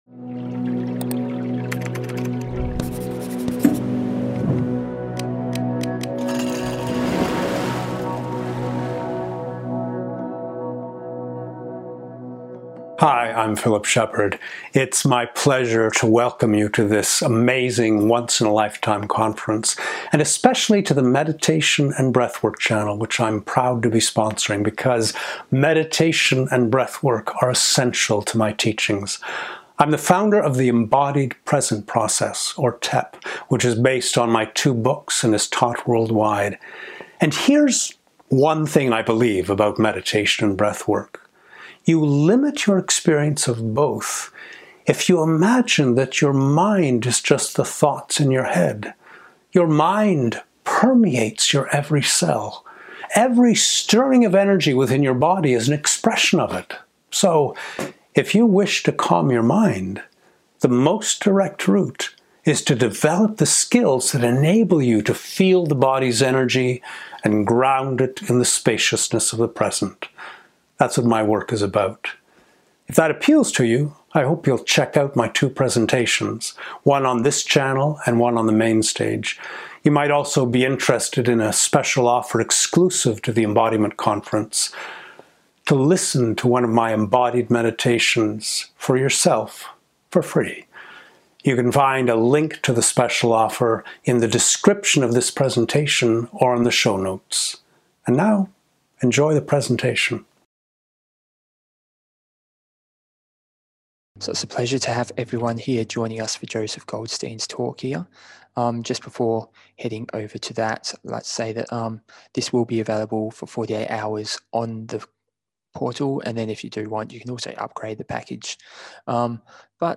An Interview with Joseph Goldstein
Likely soothing